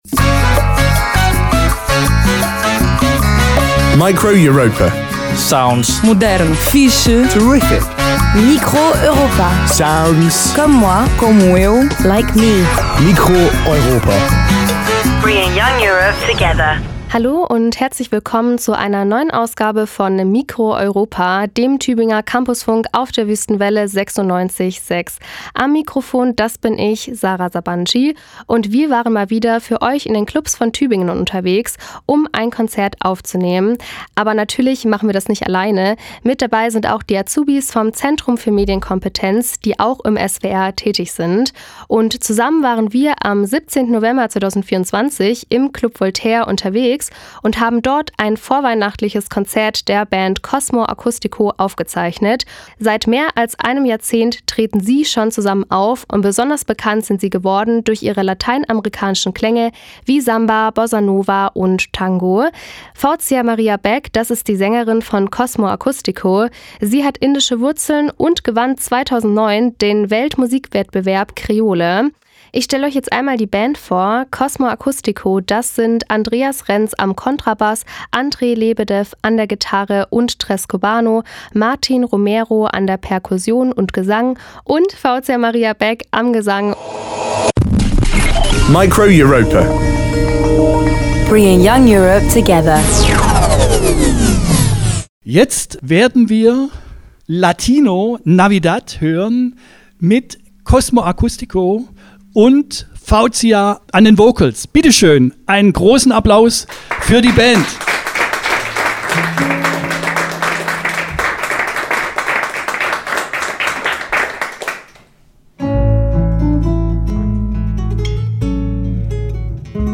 Kontrabass
Gitarre, Tres cubano
Perkussion, Gesang
Form: Live-Aufzeichnung, geschnitten